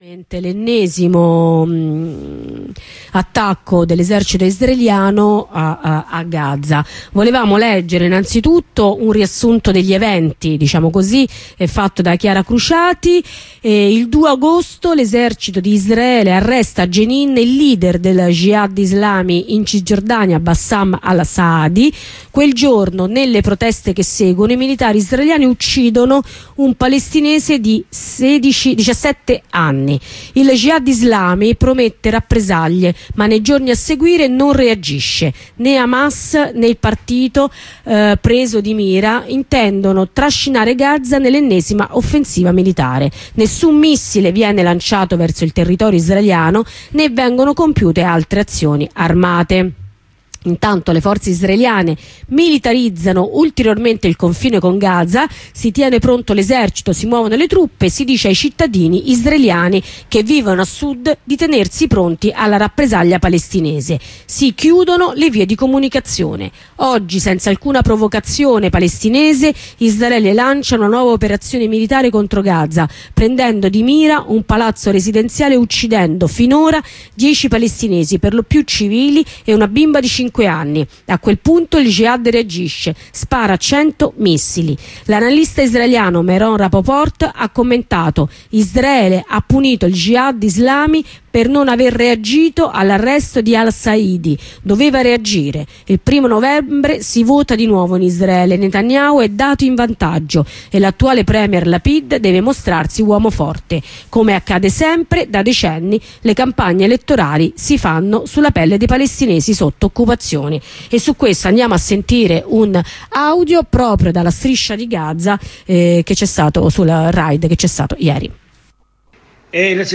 Israele inizia l'operazione Breaking Dawn, bombardata la striscia di Gaza. Sentiamo una testimonianza dalla Striscia di Gaza che ci racconta la situazione.